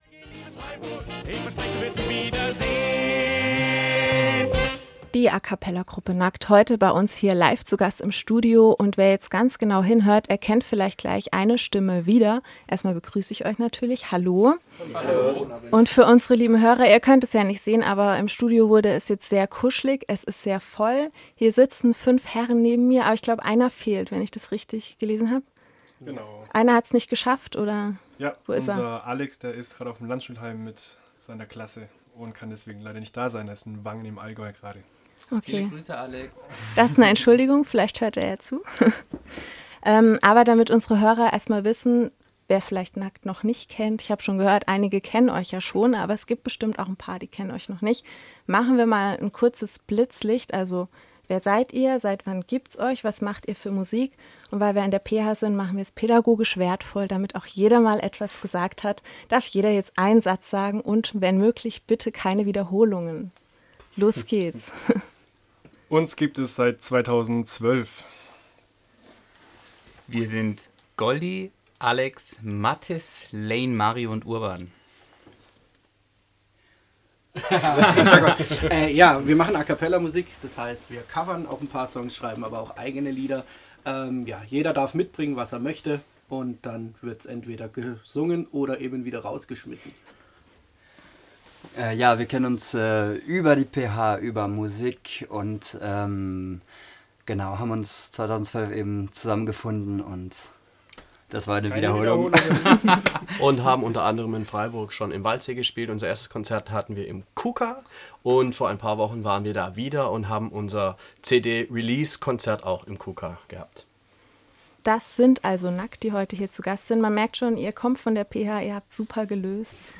Nudistische Musik mit der a capella Gruppe Nackt